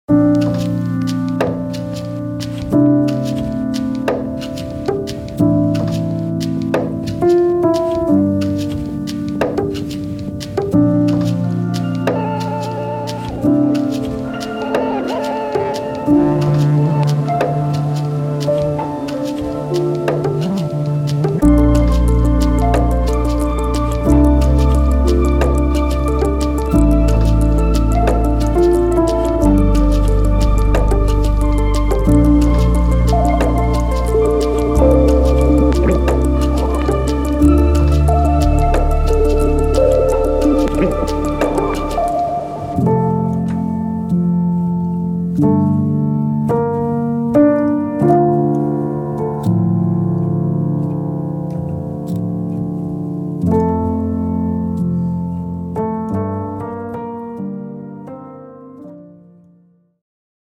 Ambient
一些类似的美学、电影般的氛围和新古典主义钢琴。